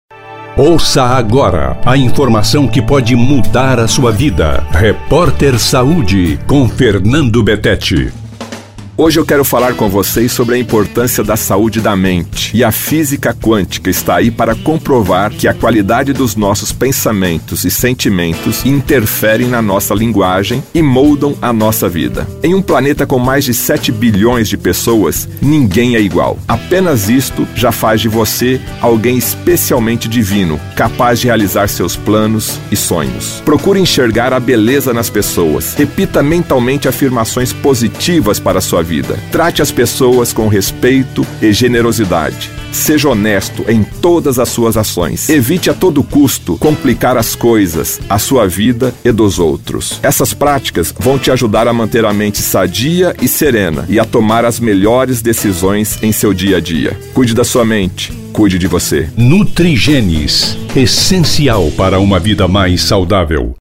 Categoria: Coluna
Periodicidade: 3 vezes por semana (segunda, quarta e sexta), gravada